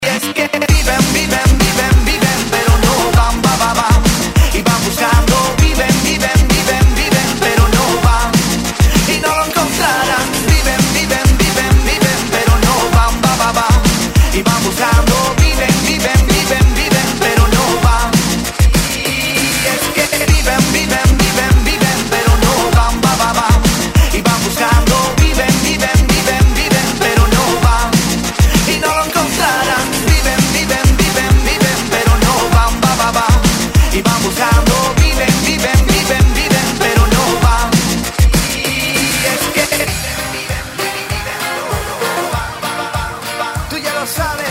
• Качество: 128, Stereo
веселые
быстрые